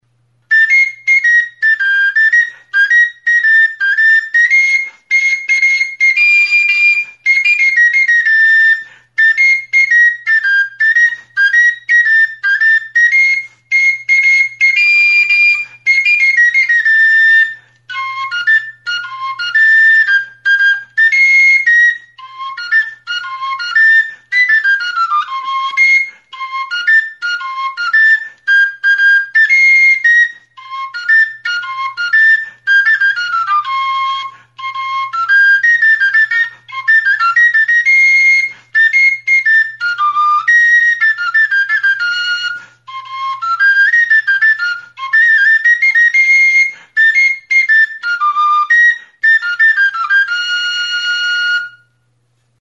Enregistré avec cet instrument de musique.
TXISTUA; POXPOLIN; Txistu metalikoa
Remarques: GIPUZKOA; DONOSTIALDEA; DONOSTIA Description: Hiru zuloko flauta zuzena da; txistu metalikoa, plastikozko ahokoarekin. Fa tonuan afinaturik dago.